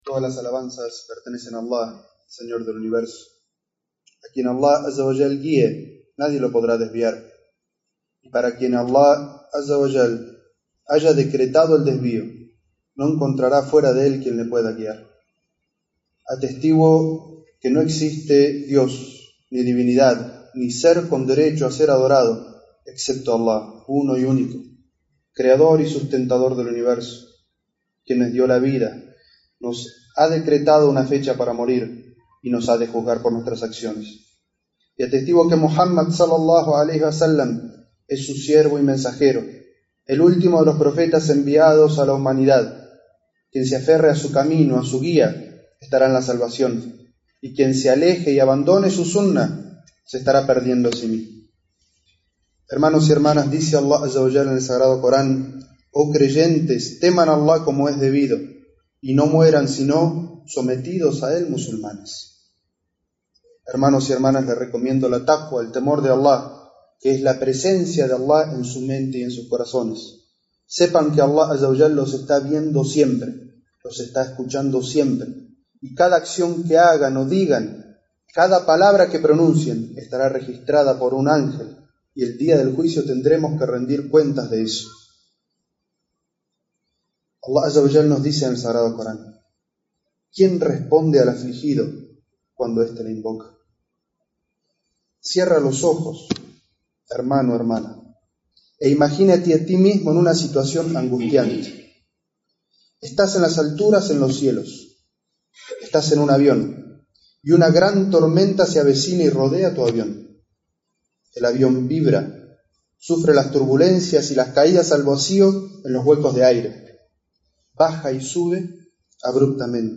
es-jutbah-quien-responde-al-afligido-cuando-este-le-invoca.mp3